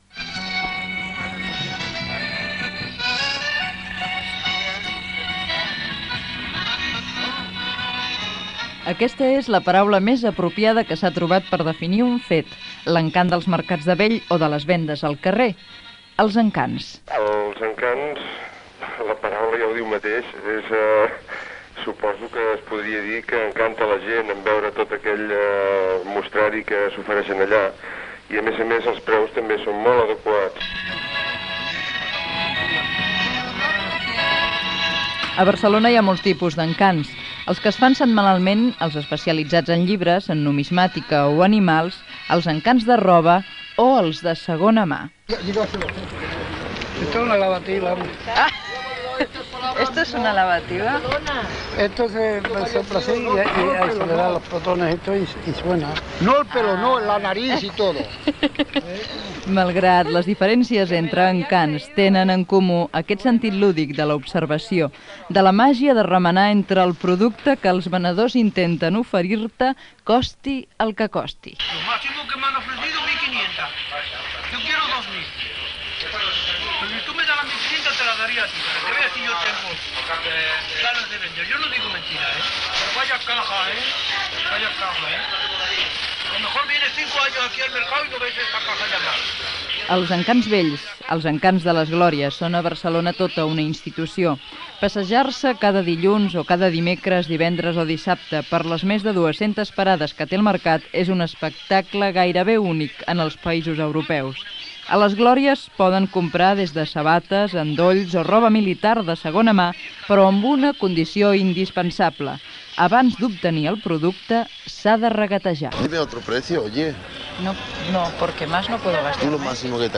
Reportatge sobre el mercat dels Encants Vells a la Plaça de les Glòries de Barcelona
Informatiu